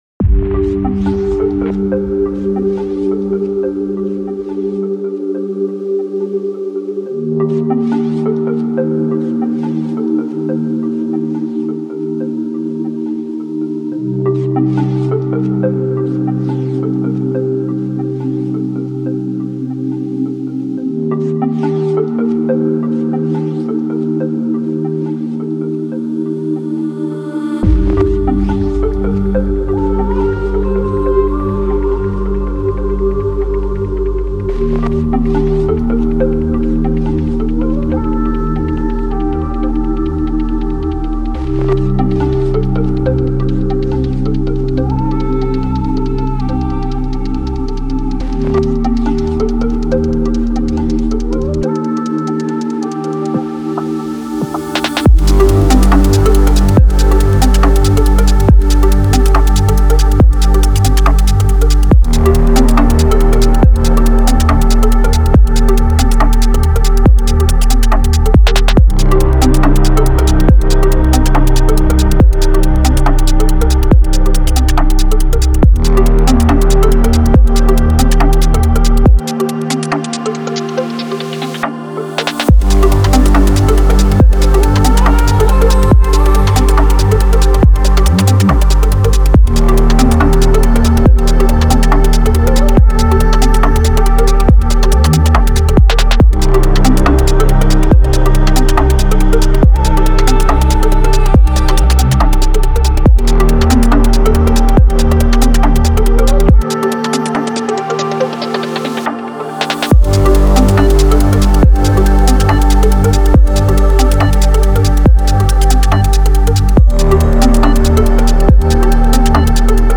это атмосферная и меланхоличная песня в жанре инди-рок.